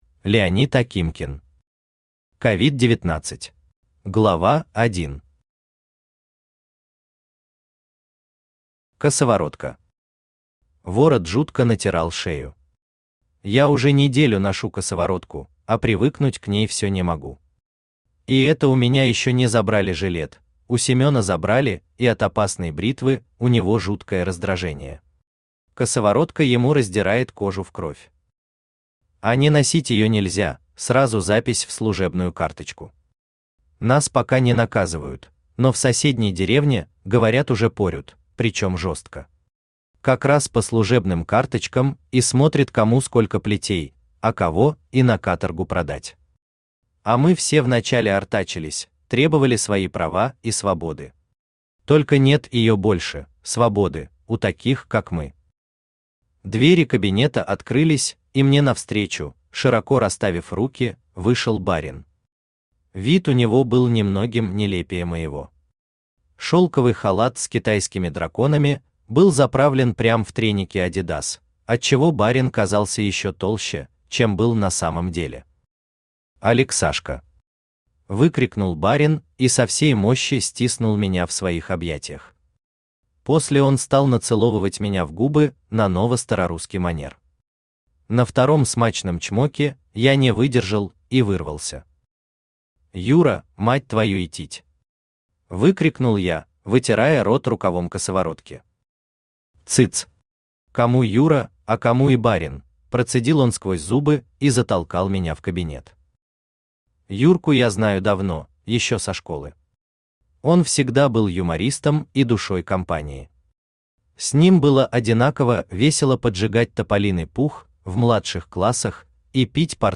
Aудиокнига COVID 19 Автор Леонид Сергеевич Акимкин Читает аудиокнигу Авточтец ЛитРес.